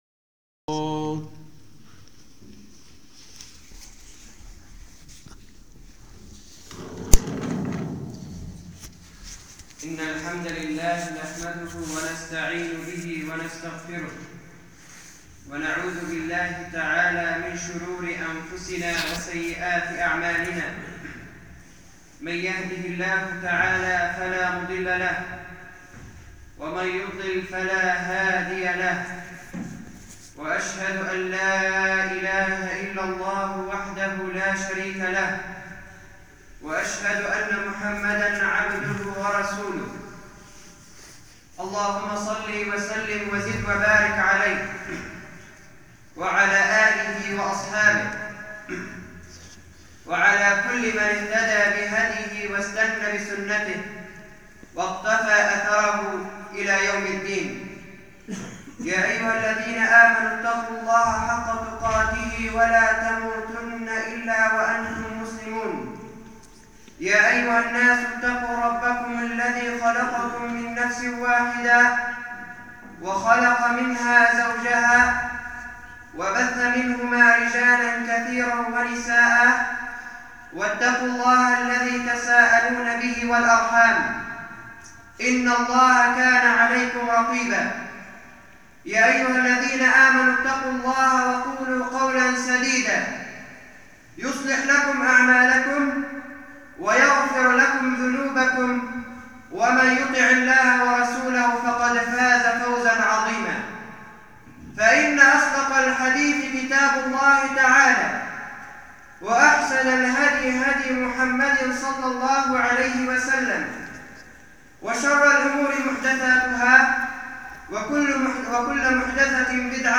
[خطبة جمعة] الغرباء
المكان: مسجد إيزال-الضنية